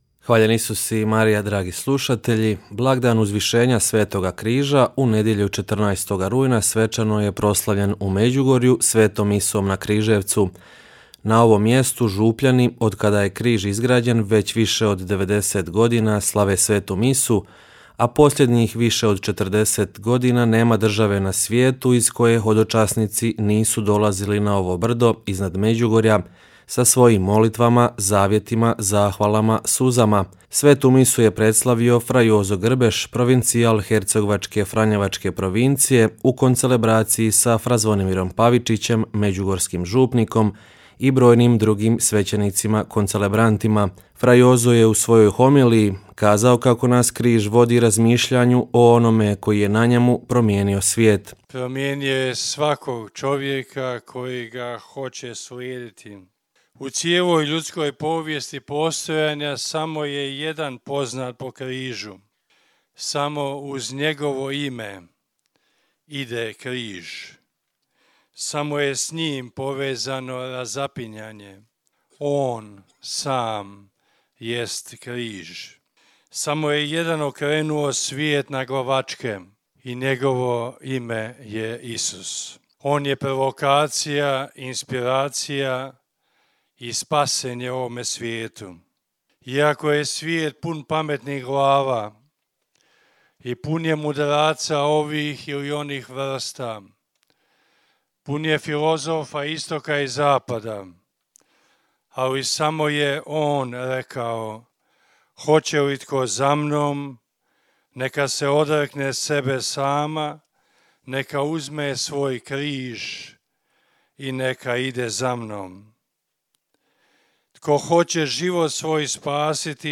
Dojmovi hodočasnika koji su u nedjelju bili na Križevcu: 'Divota je doživjeti ovo!' - Radio Mir